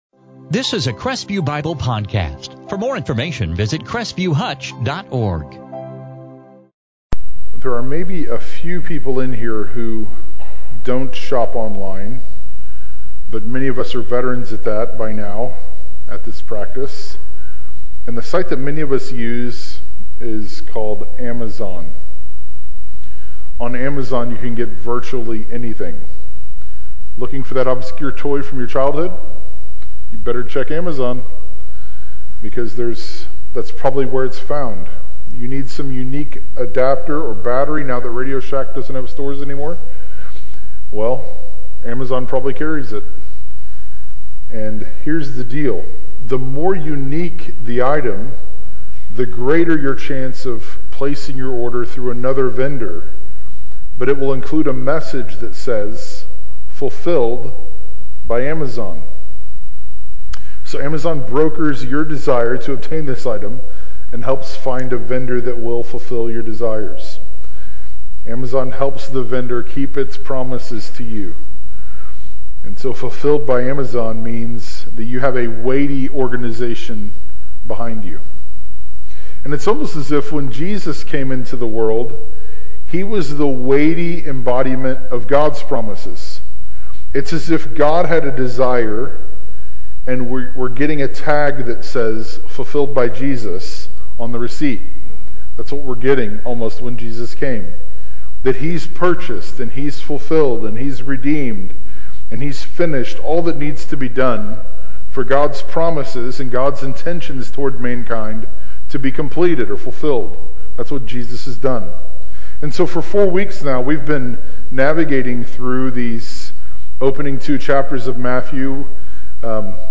Advent 2019